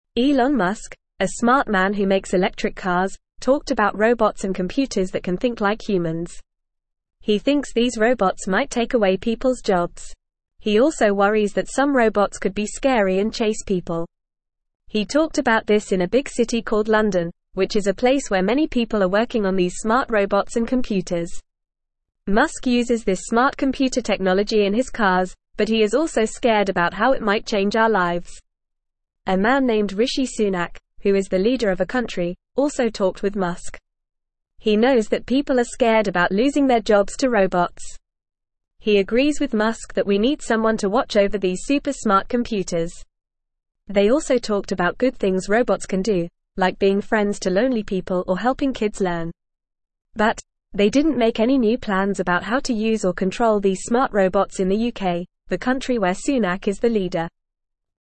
Fast
English-Newsroom-Beginner-FAST-Reading-Elon-Musk-and-Rishi-Sunak-Discuss-Smart-Robots.mp3